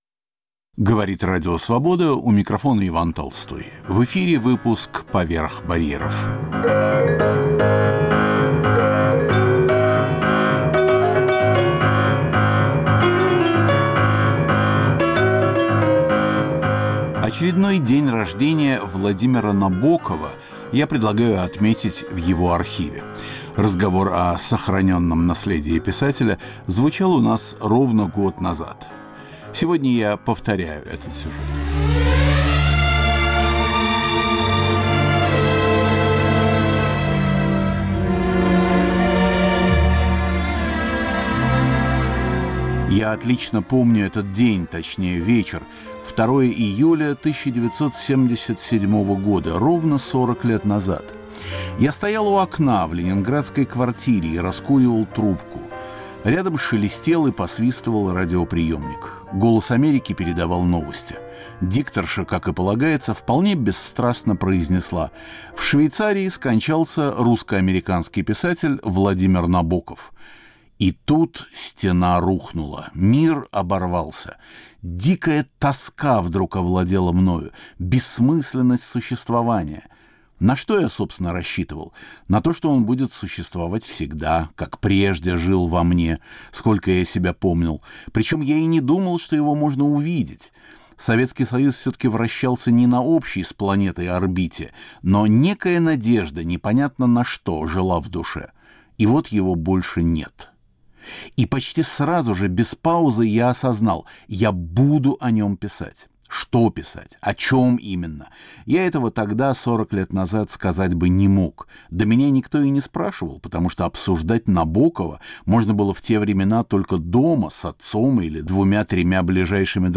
Запись 1996 года.